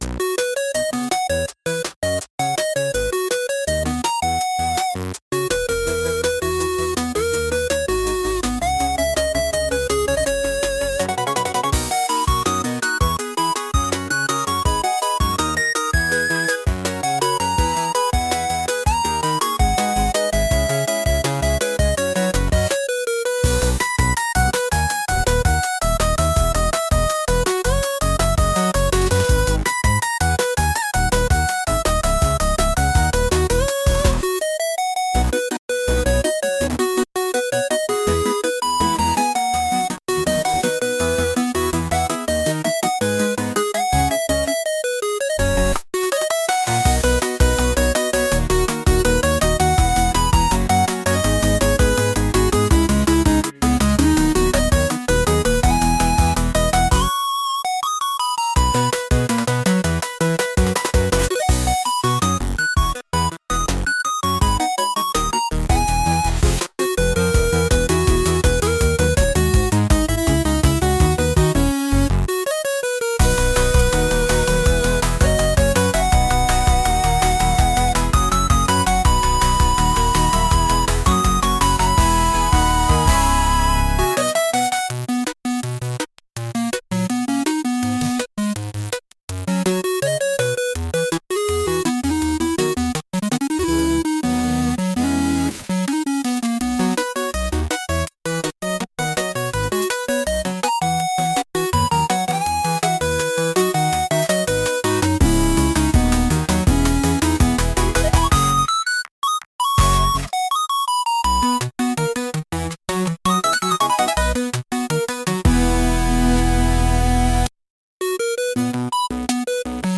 電車で出かけるような音楽です。